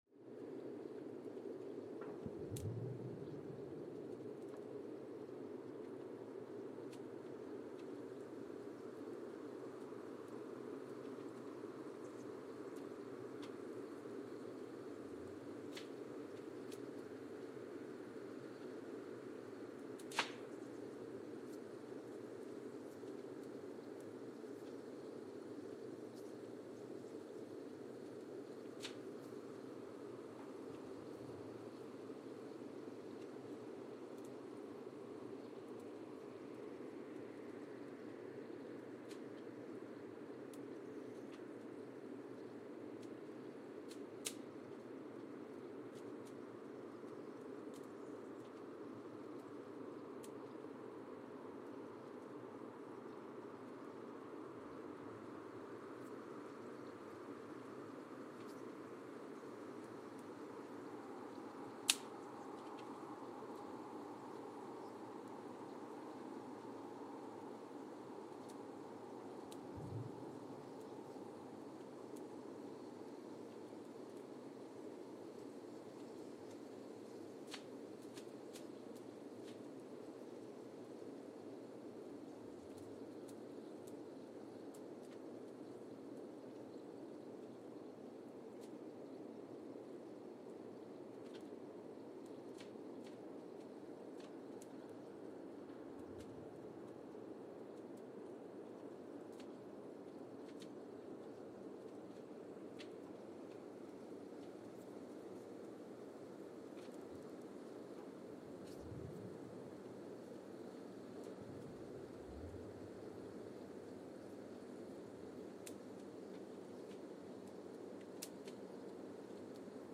Mbarara, Uganda (seismic) archived on February 26, 2018
Sensor : Geotech KS54000 triaxial broadband borehole seismometer
Speedup : ×1,800 (transposed up about 11 octaves)
Loop duration (audio) : 05:36 (stereo)